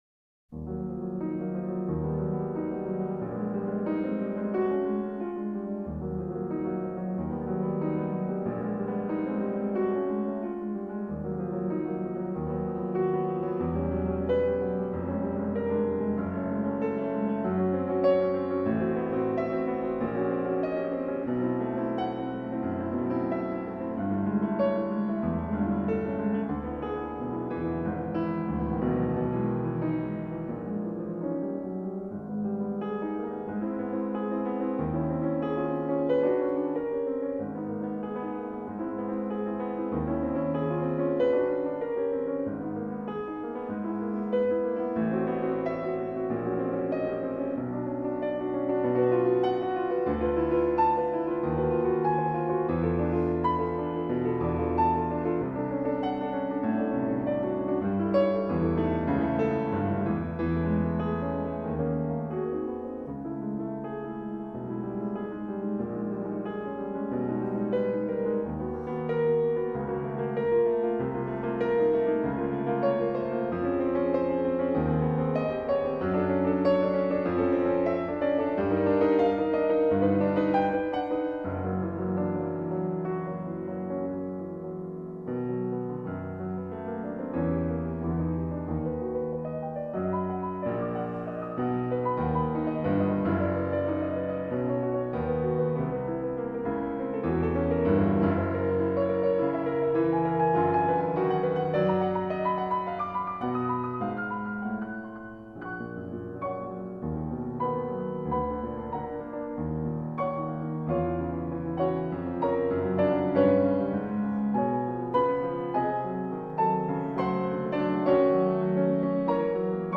Documents joints Sonate pour piano en Mib mineur ( MP3 - 10.3 Mio ) 1er Mouvement : Modérément calme et expressif. La sonate comporte 4 mouvements Jean Hubeau, Piano